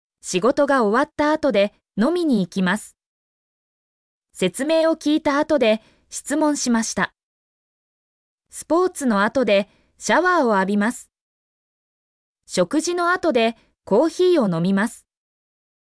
mp3-output-ttsfreedotcom-19_3z3uGvcp.mp3